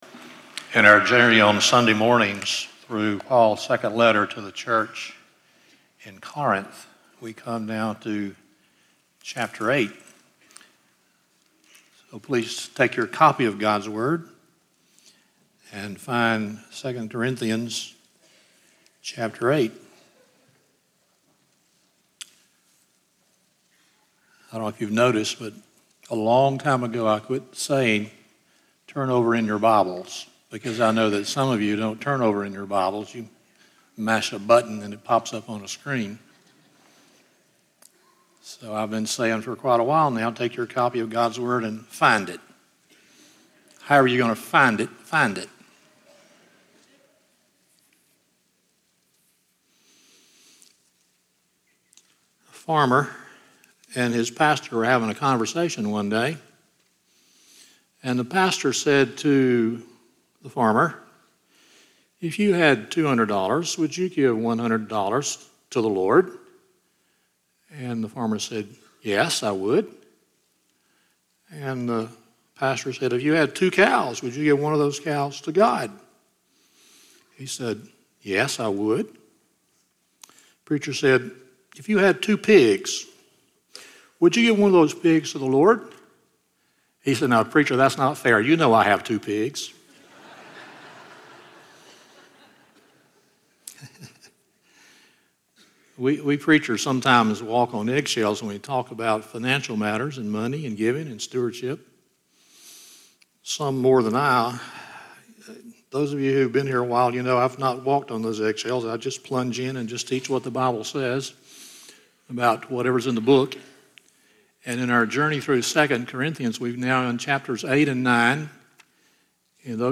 2 Corinthians 8:10-15 Service Type: Sunday Morning 1.